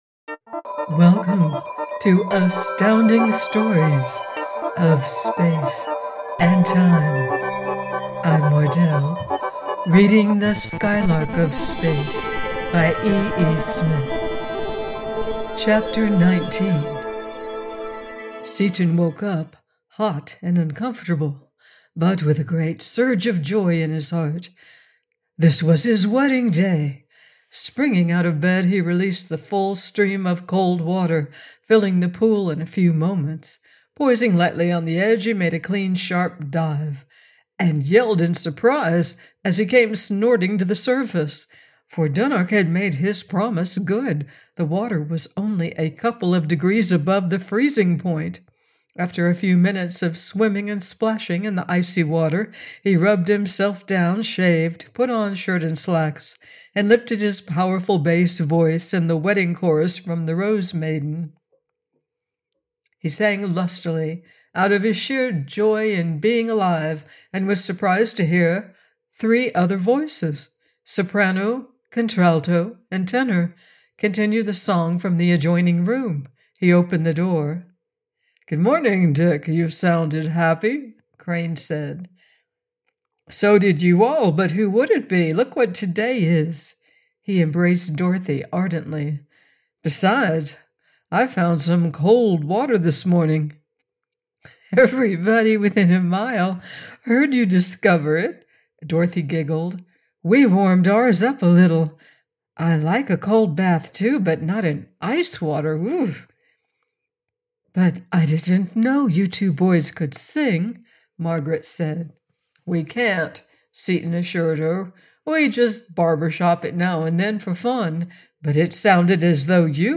The Skylark of Space – by Edward E. Smith - AUDIOBOOK